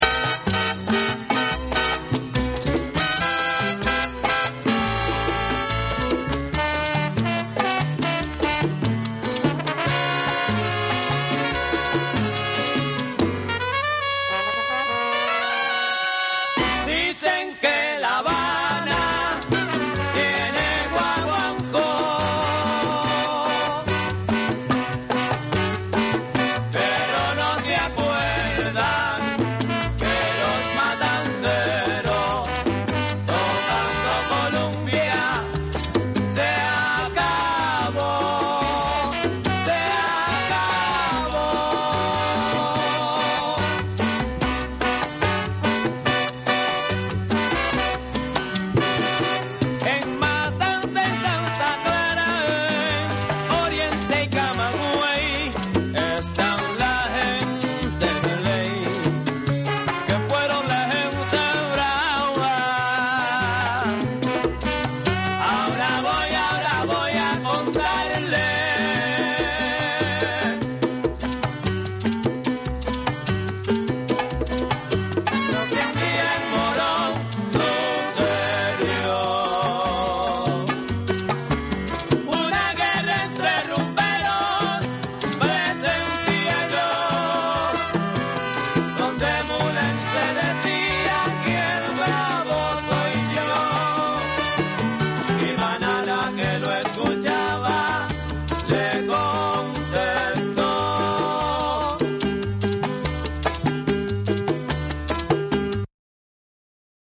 サルサ・シンガー